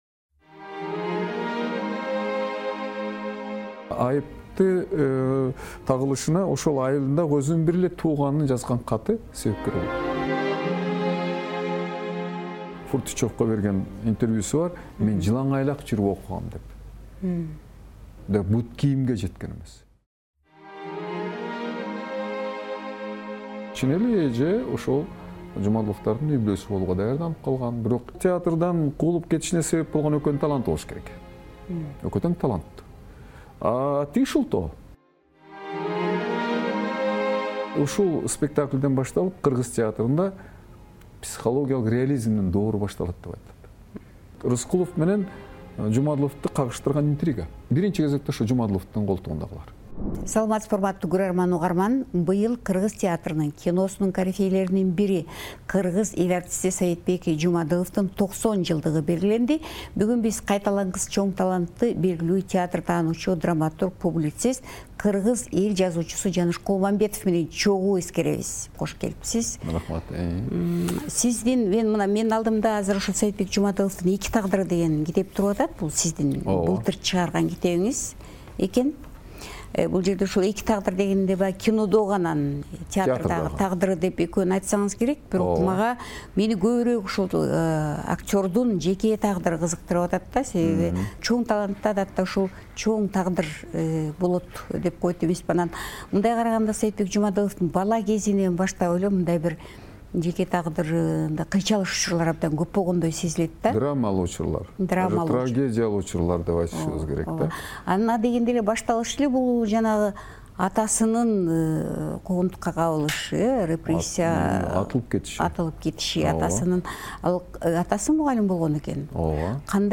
"Азаттыкка" курган маегинде берди.